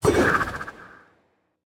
Minecraft Version Minecraft Version snapshot Latest Release | Latest Snapshot snapshot / assets / minecraft / sounds / item / trident / riptide1.ogg Compare With Compare With Latest Release | Latest Snapshot
riptide1.ogg